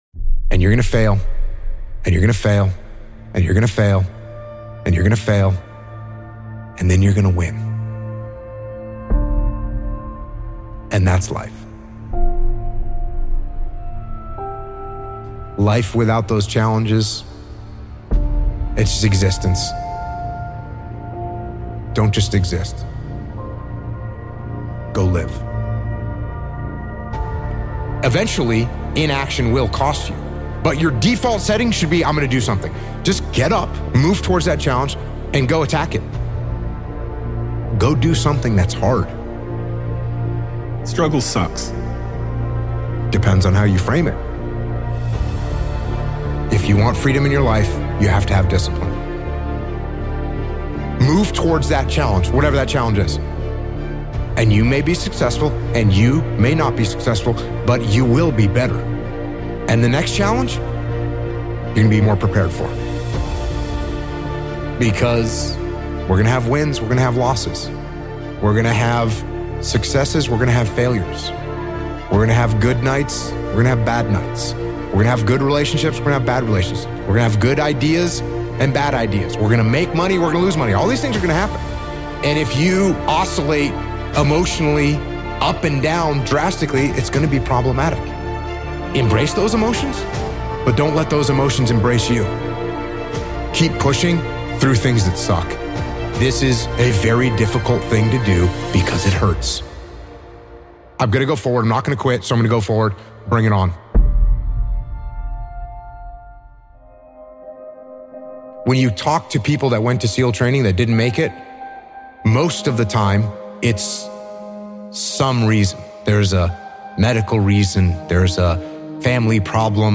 Fail Fail Fail... Until You Win! - Jocko Willink | Most Dangerous Motivational Speech 8:45